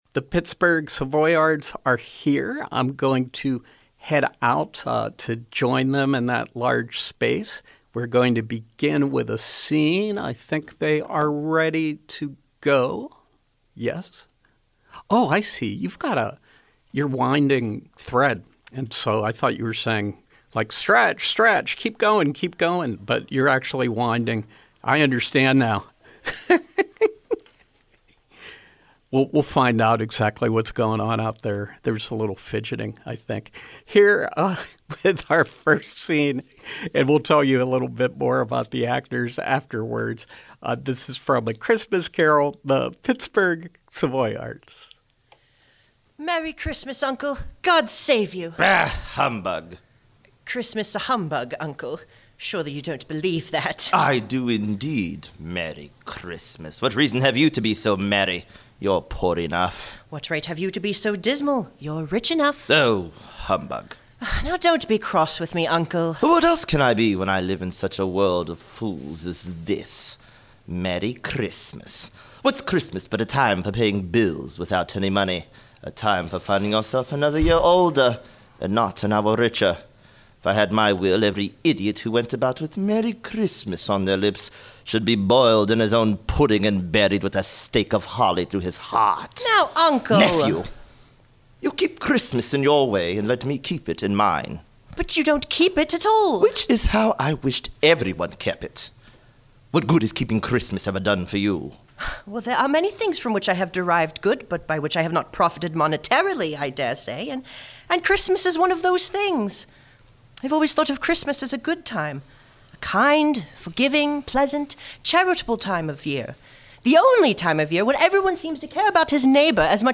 Live Performance: A Christmas Carol, Pittsburgh Savoyards